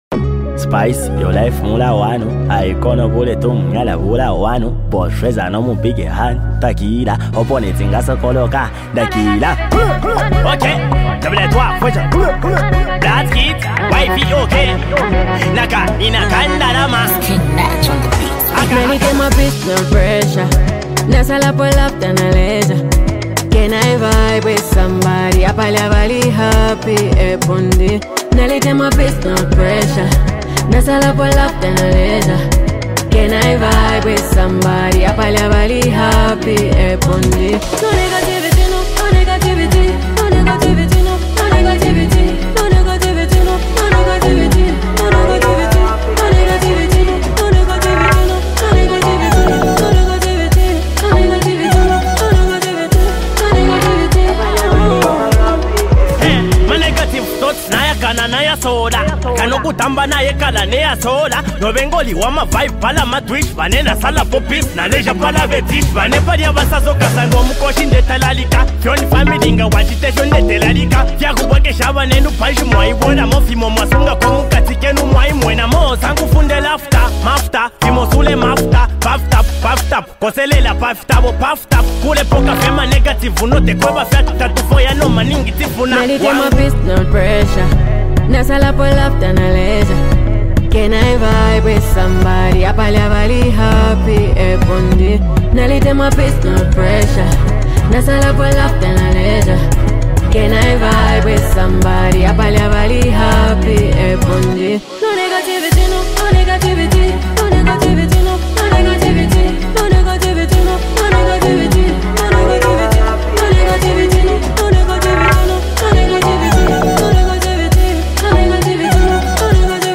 Talented female singer and rapper